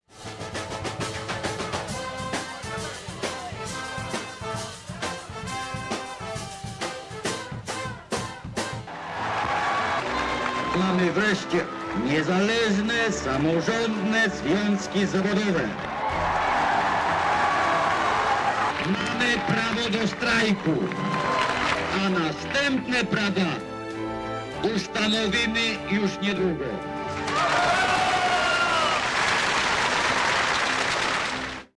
Punktem centralnym uroczystości było spotkanie przed stoczniową bramą.
Później zabrzmiały historyczne słowa Lecha Wałęsy: